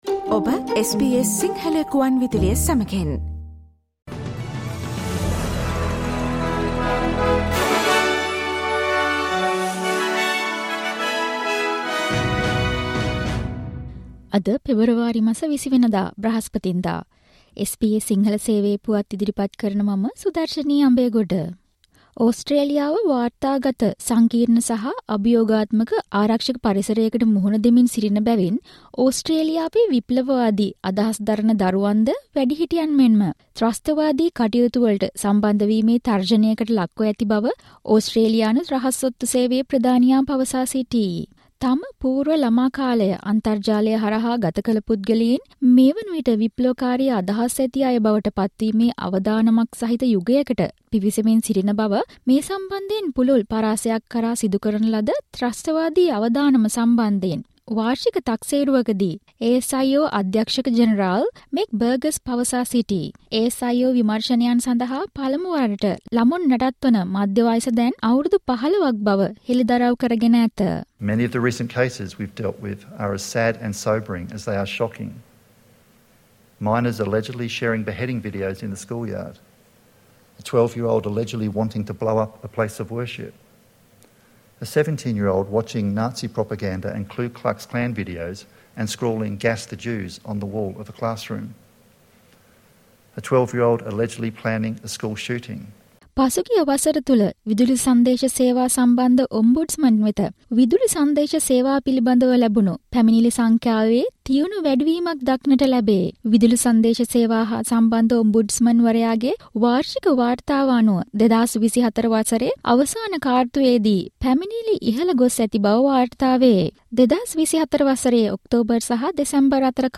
SBS Sinhala Newsflash 20 Feb: ASIO warns of risk of radicalisation among children who spend more time online